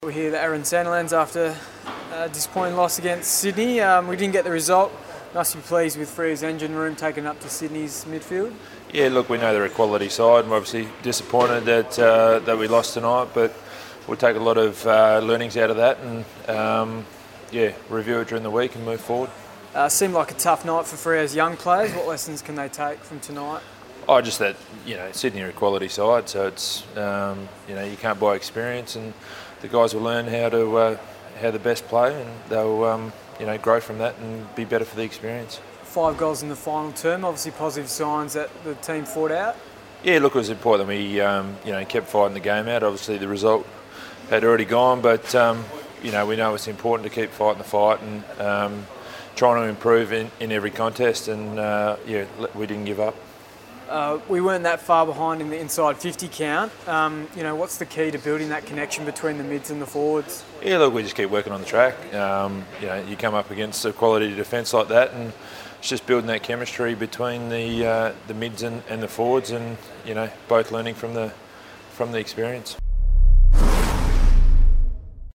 Aaron Sandilands post-match interview - Round 9 v Swans